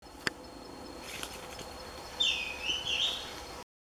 Flautim (Schiffornis virescens)
Nome em Inglês: Greenish Schiffornis
Província / Departamento: Misiones
Localidade ou área protegida: Reserva Privada y Ecolodge Surucuá
Condição: Selvagem
Certeza: Gravado Vocal